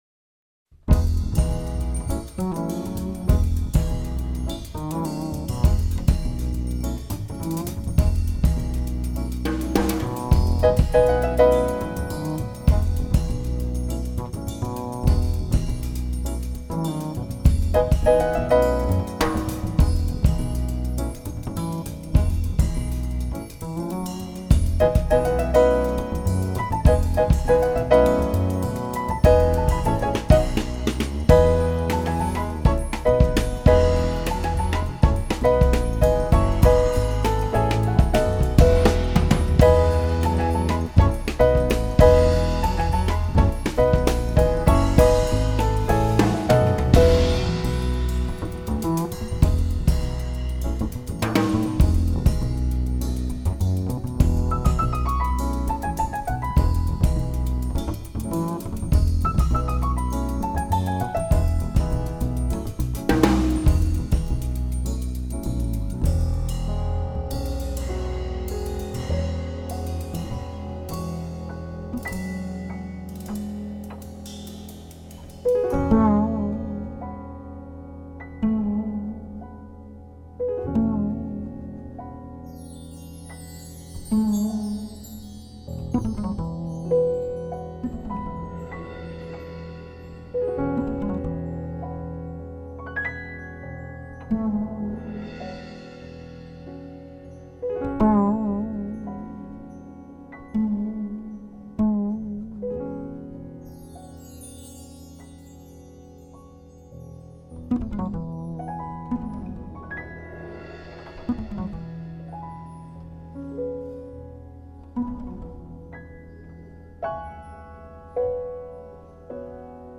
Вивальди - Аллегро  к-т Соль минор (совр обработка)
А. Вивальди в джазовой обработке